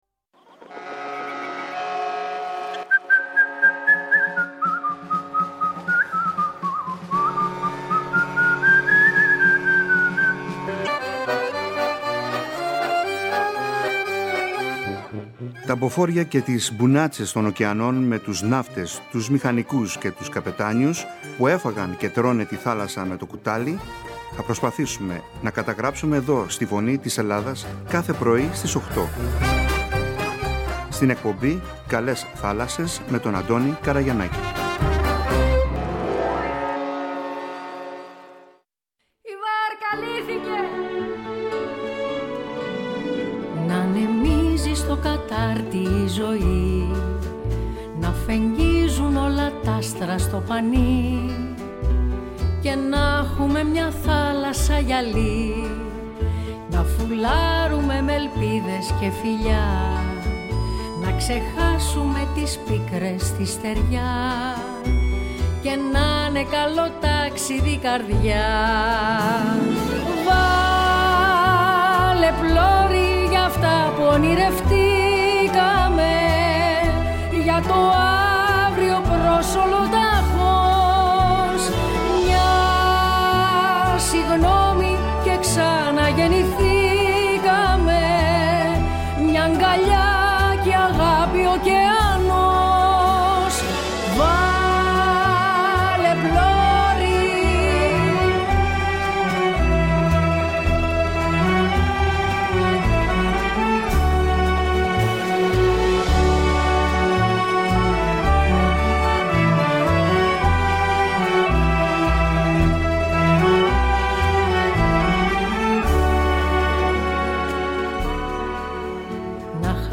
Σήμερα οι «ΟΙ ΚΑΛΕΣ ΘΑΛΑΣΣΕΣ» βρέθηκαν στην Χώρα της Χίου